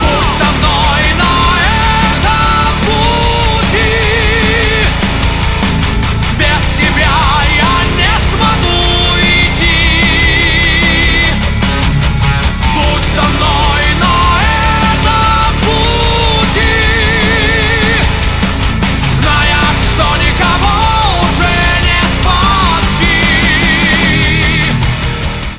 • Качество: 192, Stereo
Классический рок с великолепный вокалом